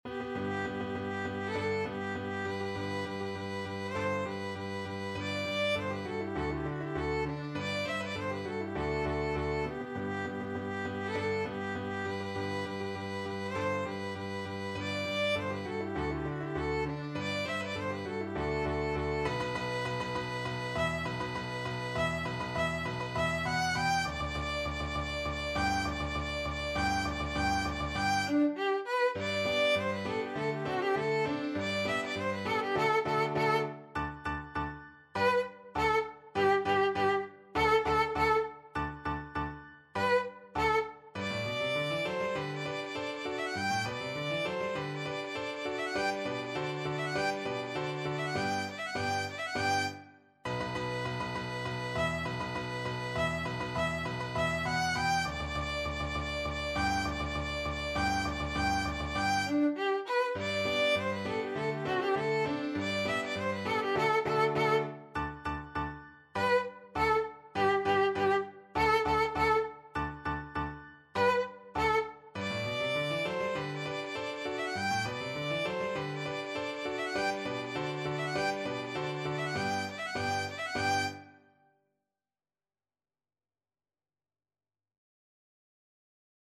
Classical Beethoven, Ludwig van Yorckscher March (2 Marches for Military Band, WoO 18, No. 1) Violin version
G major (Sounding Pitch) (View more G major Music for Violin )
March = c.100
2/2 (View more 2/2 Music)
D5-G6
Classical (View more Classical Violin Music)